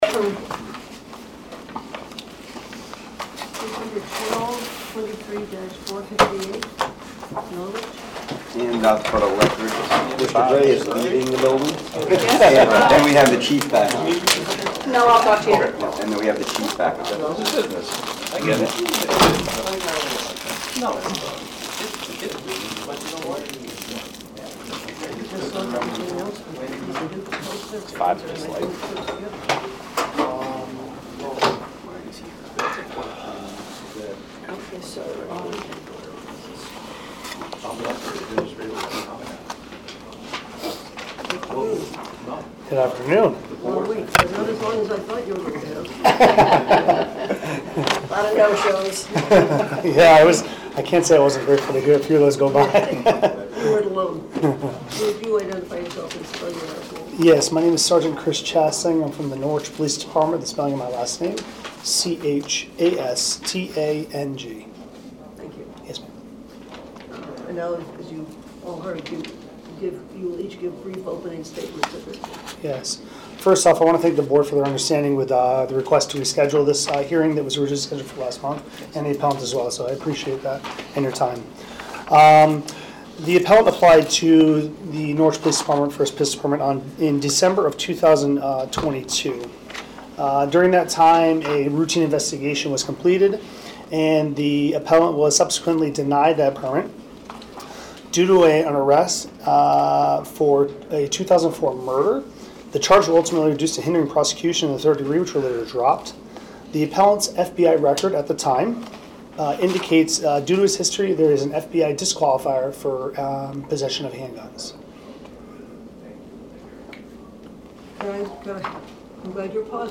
Meeting of the Board of Firearms Permit Examiners